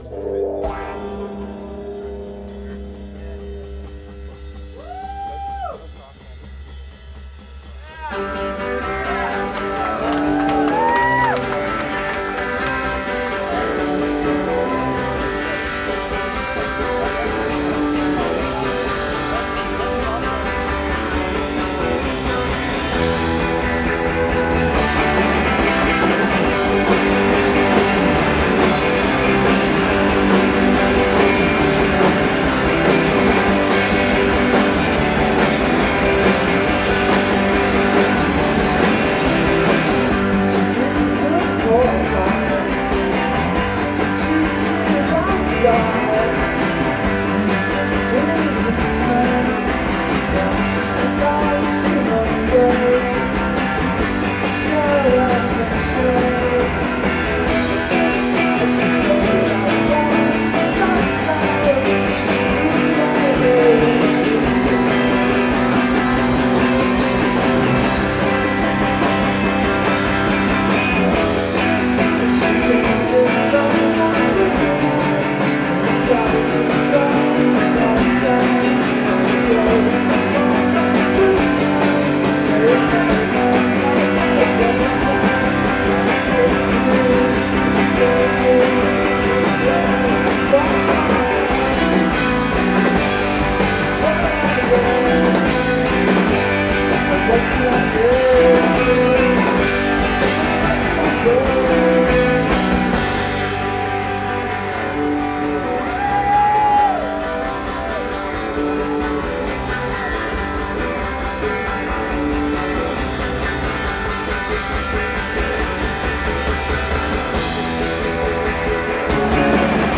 Live at All Tomorrow’s Parties NYC 2008
in Monticello, NY